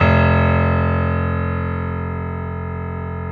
55p-pno03-F0.wav